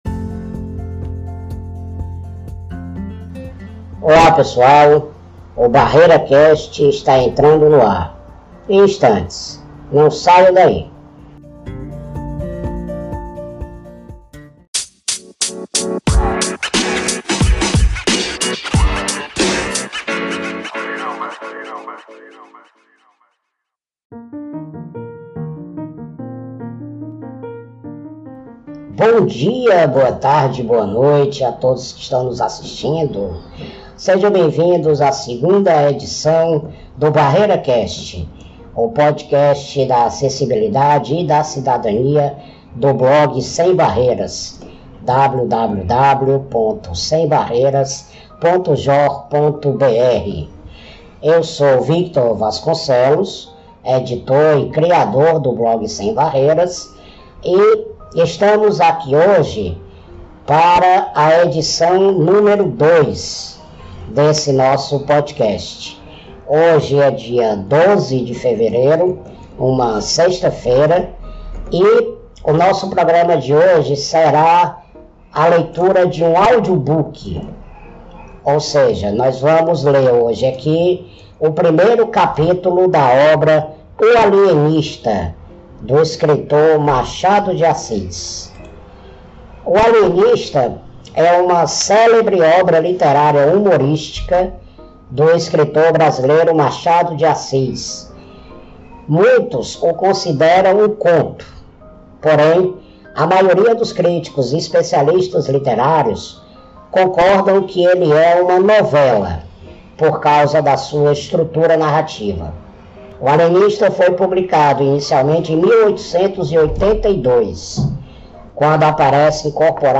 faz a leitura do primeiro capítulo do livro O Alienista, de Machado de Assis.